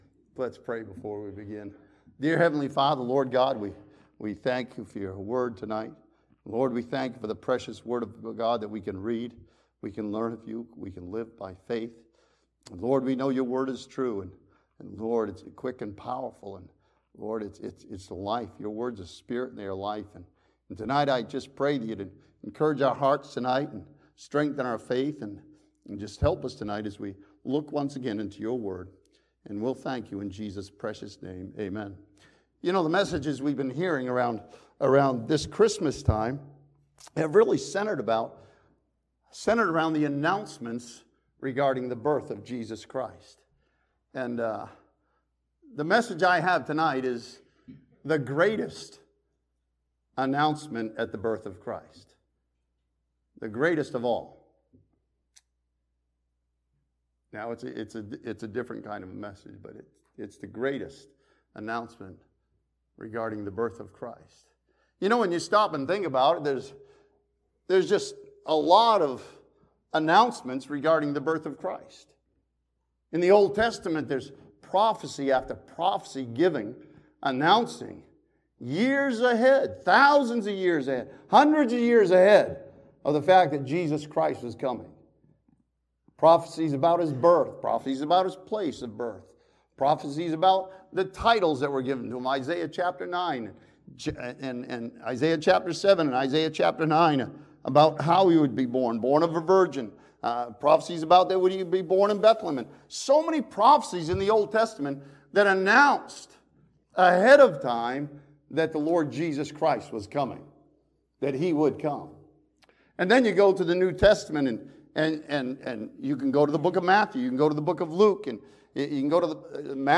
This sermon from Hebrews chapter 10 studies the greatest announcement about the coming of Jesus Christ as Savior.